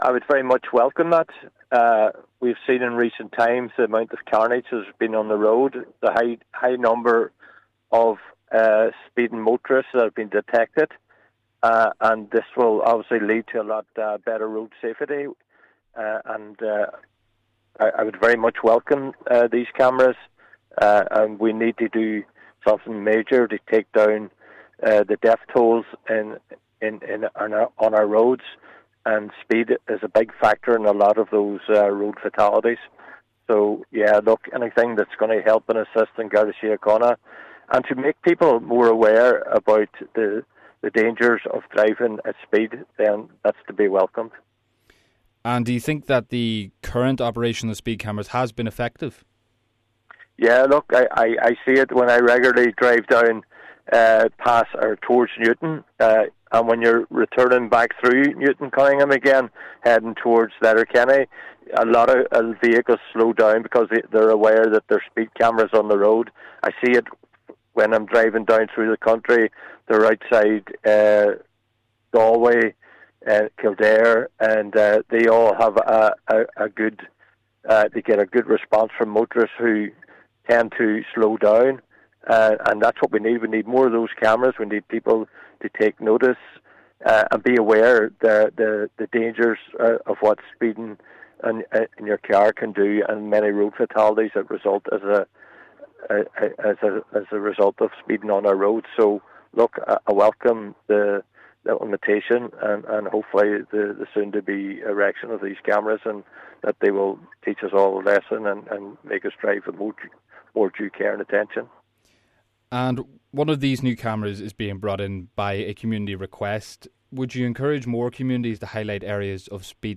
Chair of the Donegal Local Community Safety Partnership, Cllr Gerry McMonagle, has welcomed the move: